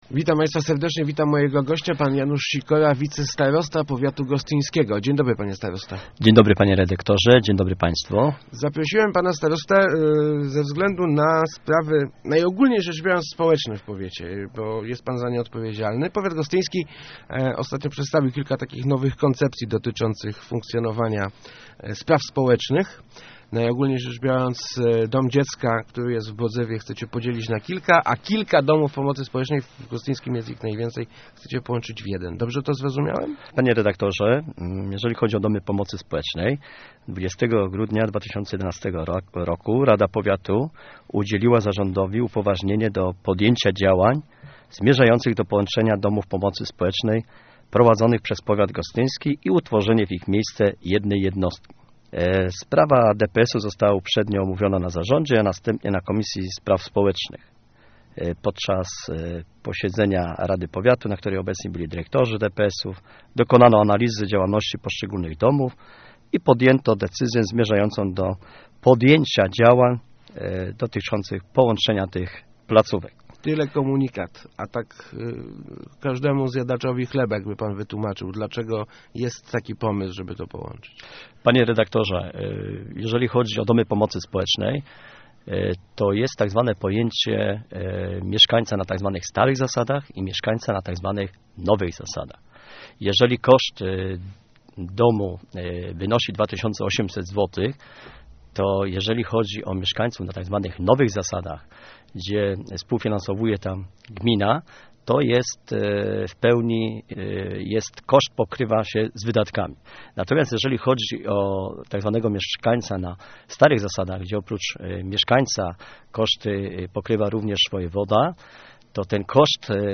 Ze starostą o domu dziecka i DPS-ach
Powiat gostyński szuka sposobu na redukcję kosztów utrzymania domów pomocy społecznej. Nikt nie straci pracy - zapowiadał w Rozmowach Elki wicestarosta Janusz Sikora. Zmieni się też struktura Domu Dziecka w Bodzewie - to wynik znowelizowania ustawy o pieczy zastępczej.